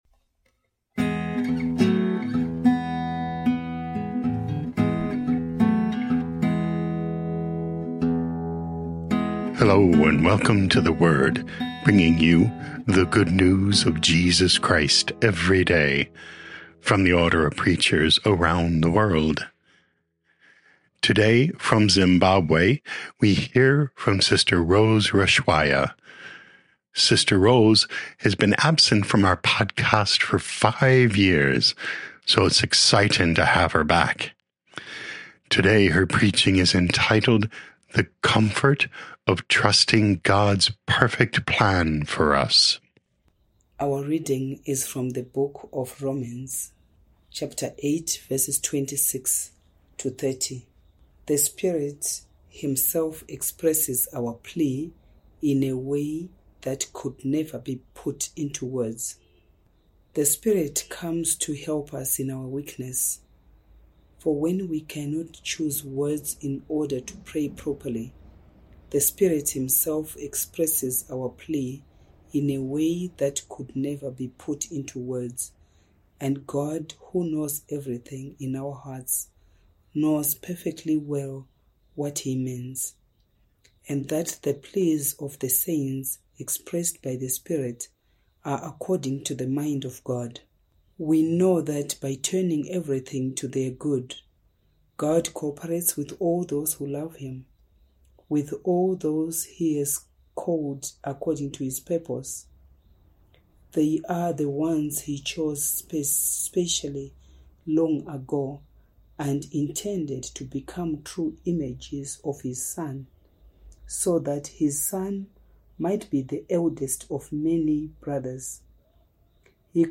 29 Oct 2025 The Comfort of Trusting God’s Perfect Plan for Us Podcast: Play in new window | Download For 29 October 2025, Wednesday of week 30 in Ordinary Time, based on Romans 8:26-30, sent in from Bulawayo, Zimbabwe.